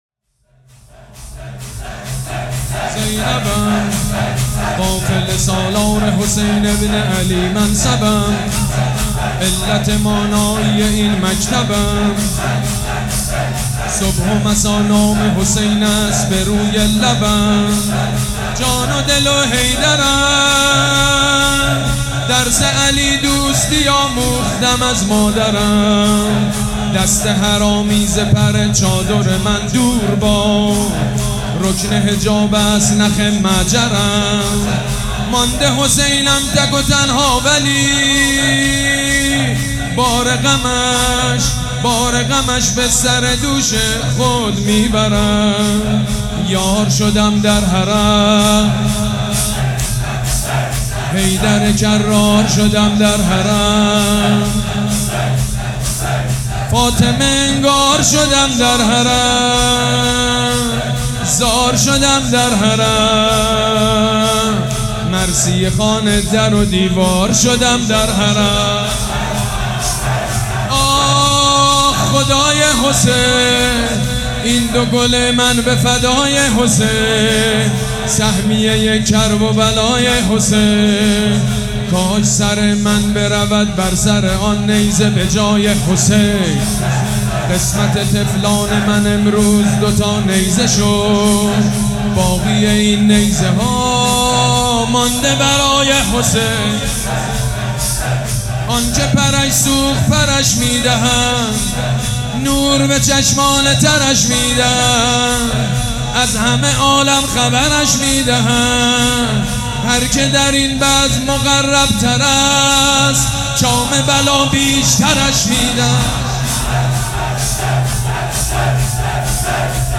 شور
مداح
مراسم عزاداری شب چهارم